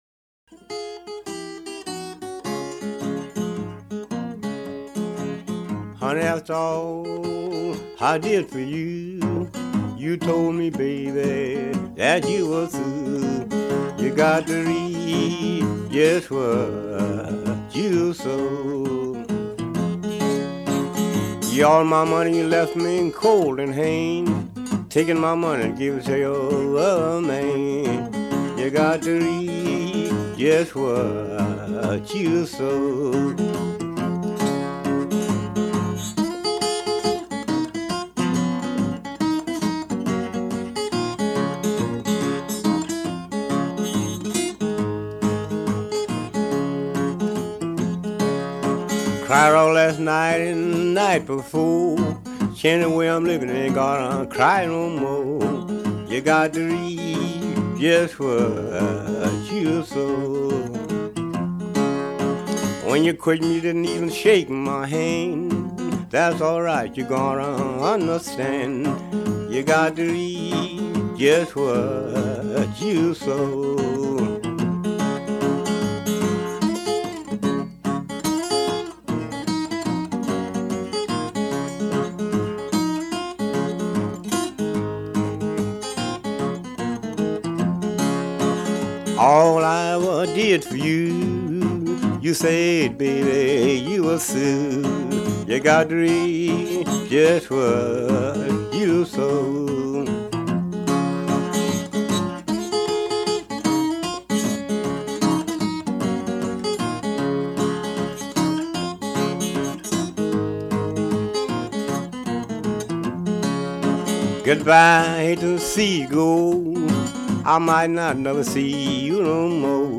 blues, country, and religious